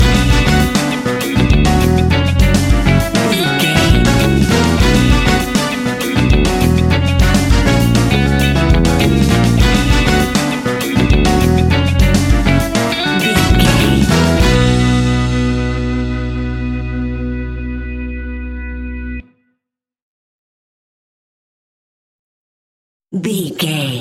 Aeolian/Minor
World Music
uptempo
brass
saxophone
trumpet
fender rhodes
clavinet